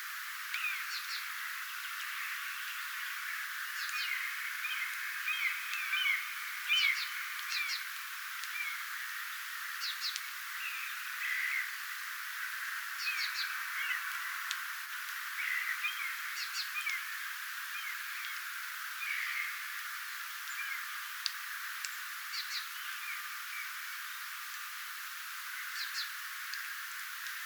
erikoista sinitiaisen ääntelyä
onko_tuo_sinitiainen.mp3